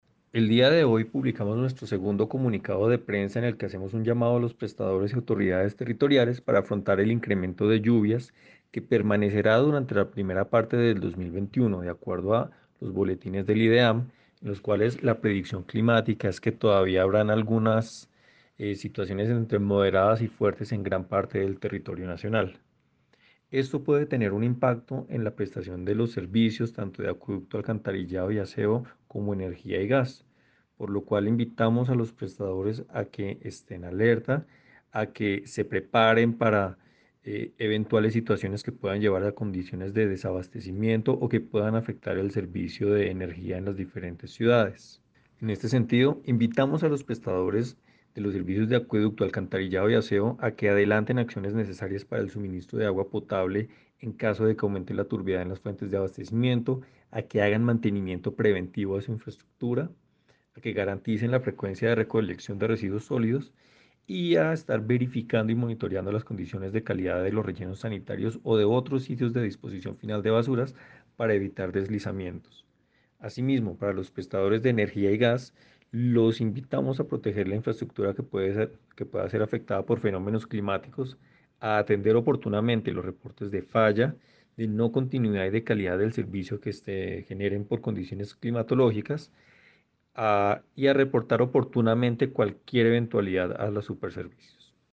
Audio declaraciones superintendente (e) Víctor Hugo Arenas